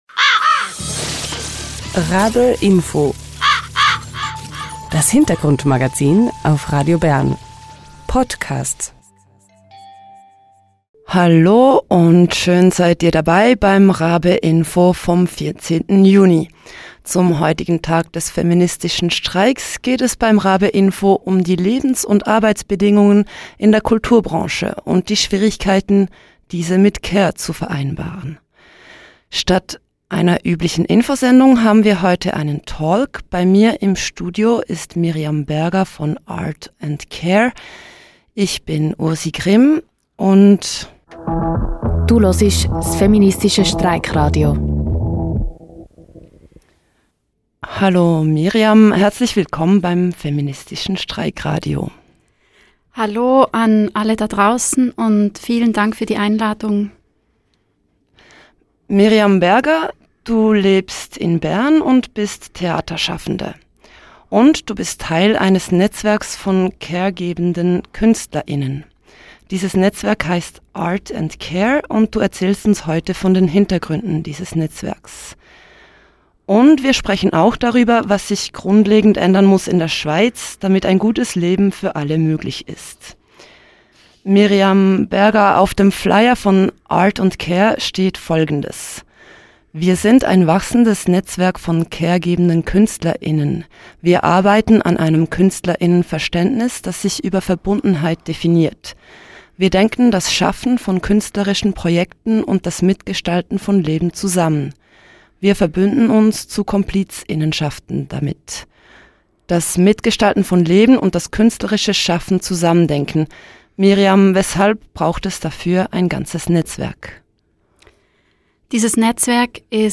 Beschreibung vor 1 Jahr Talk zum feminsitischen Streik: Anstelle des RaBe-Infos gibt es heute einen sendungsfüllenden Talk über die Lebens- und Arbeitsbedingungen von care-gebenden Künstler:innen.